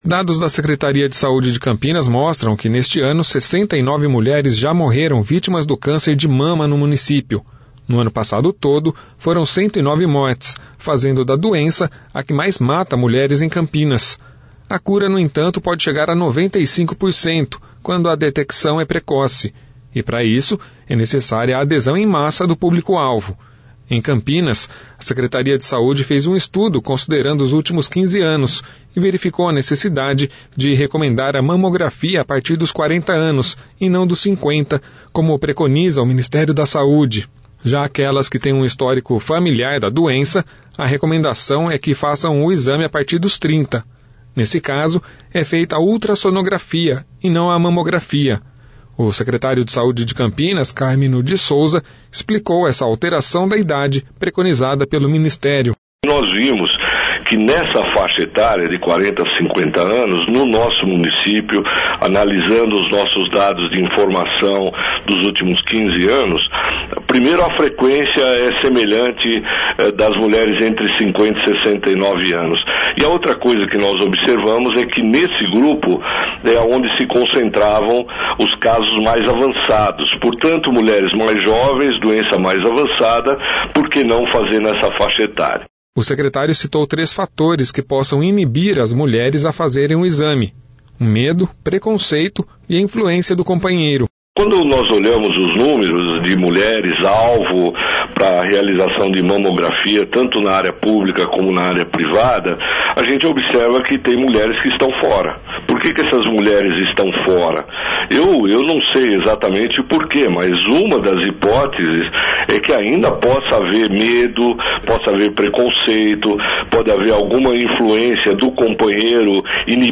O secretário de saúde de Campinas, Cármino de Souza, explicou essa alteração da idade preconizada pelo Ministério